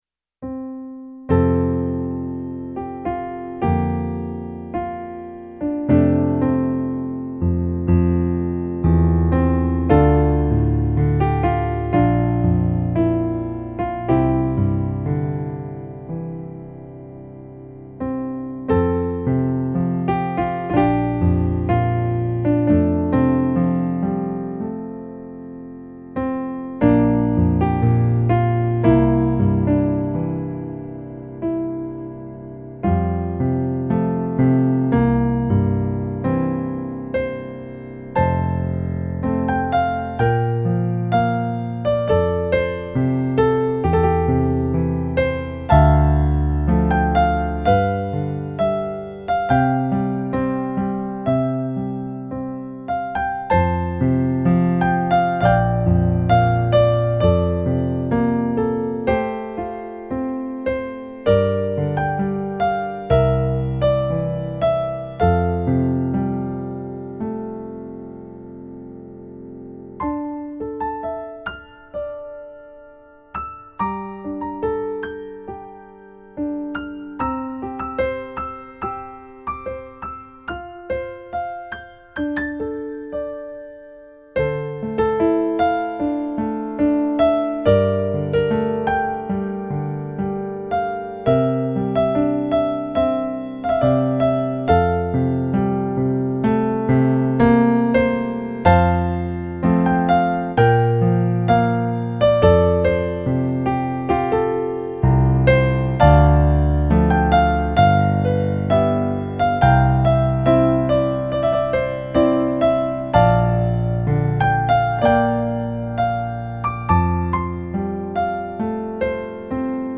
contains eight piano solo arrangements.
pop ballad remix